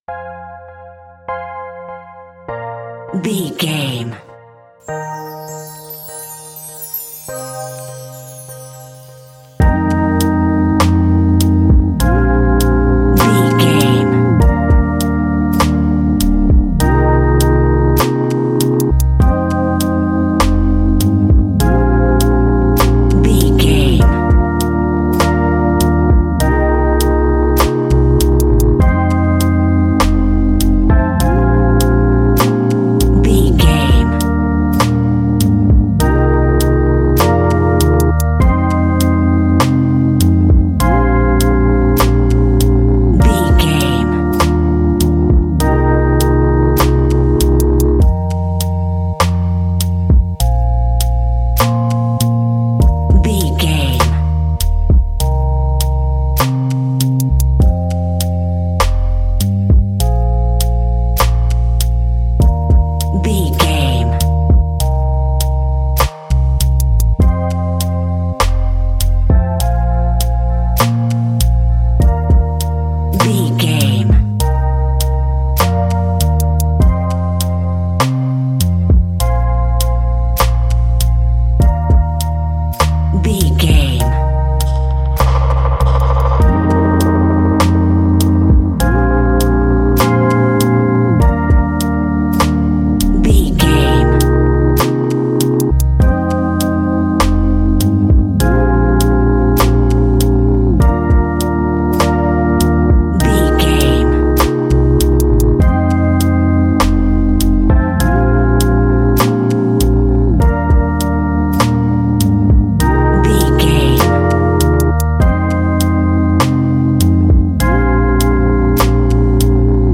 Ionian/Major
A♯
laid back
Lounge
sparse
new age
chilled electronica
ambient
atmospheric